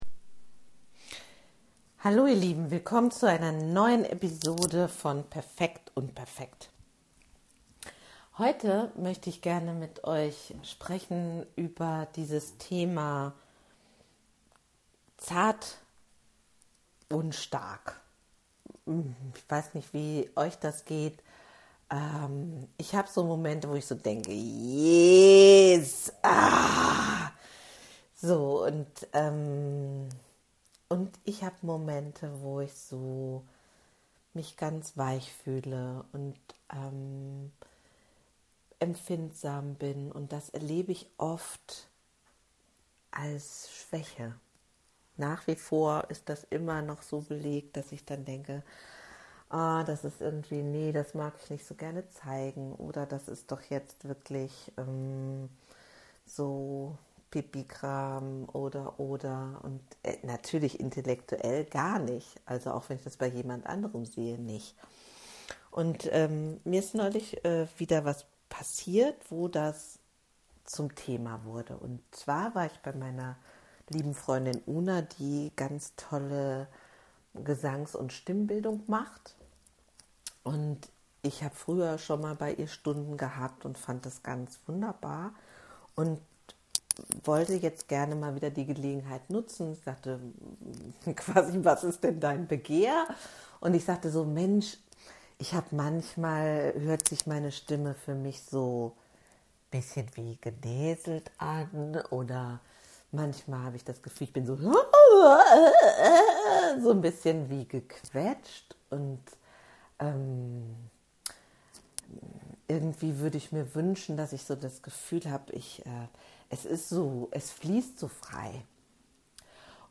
Achtung im ersten Drittel wird es etwas lauter :)